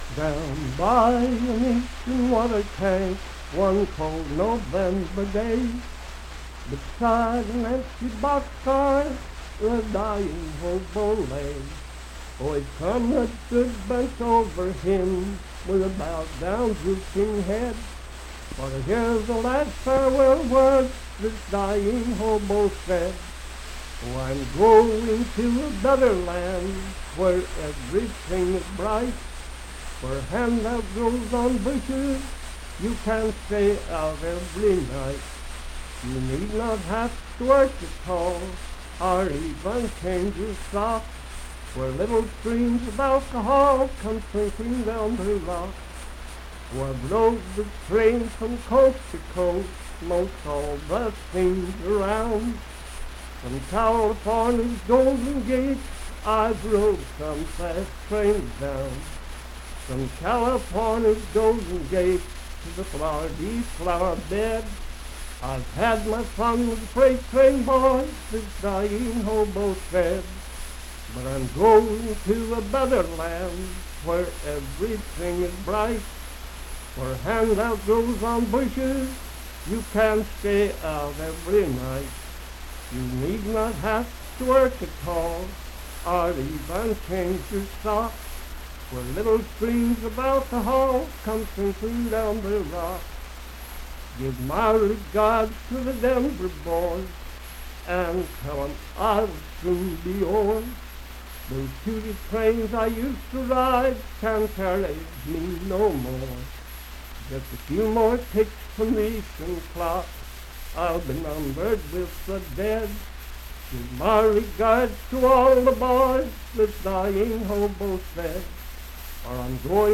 Unaccompanied vocal music
Voice (sung)
Grant County (W. Va.)